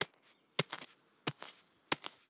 SFX_Hacke_02.wav